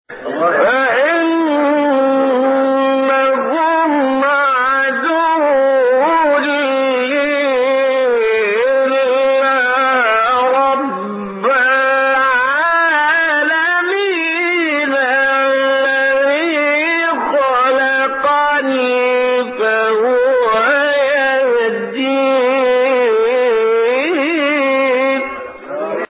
به گزارش خبرگزاری بین المللی قرآن(ایکنا) هشت فراز صوتی از محمد محمود رمضان، قاری برجسته مصری در کانال تلگرامی قاریان مصری منتشر شده است.
این فرازها از تلاوت سوره شعراء است که در مقام‌های بیات، کرد، صبا، حجاز، نهاوند، رست، چهارگاه و سه‌گاه اجرا شده است.
مقام سه گاه